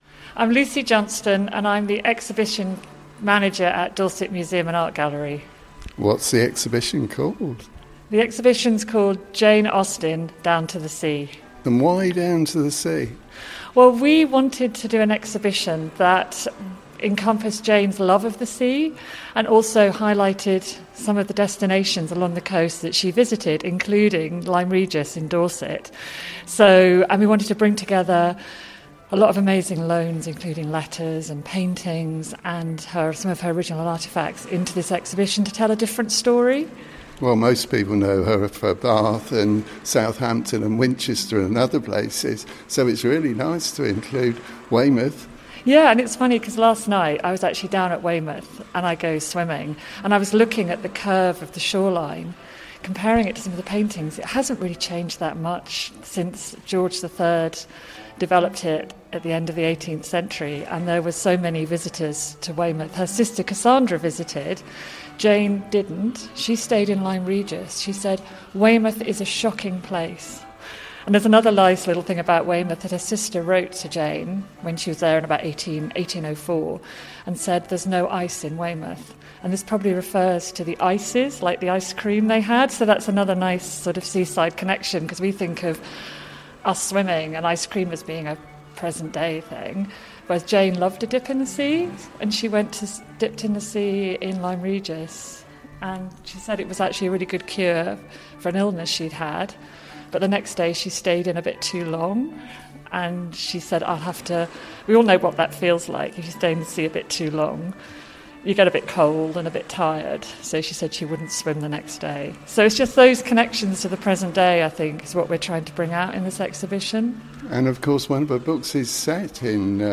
The Community Radio Station covering Central-Southern Dorset, run by volunteers and not-for-profit